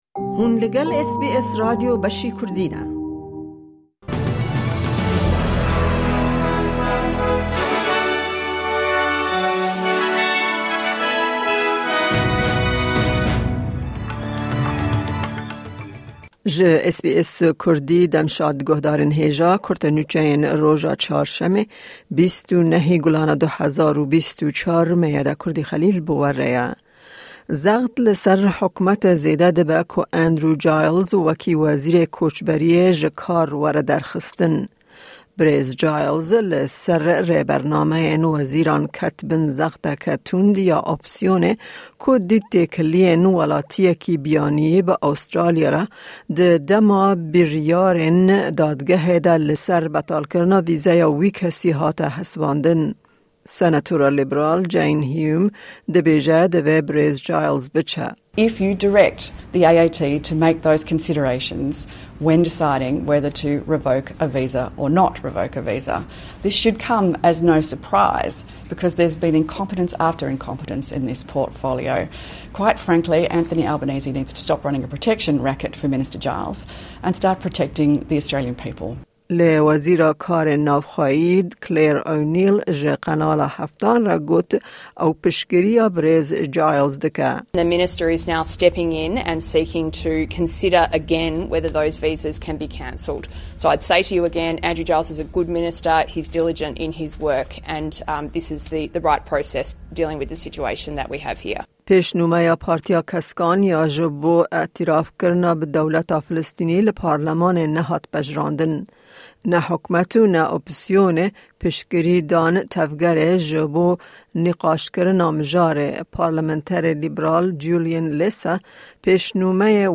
Kurte Nûçeyên roja Çarşemê 29î Gulana 2024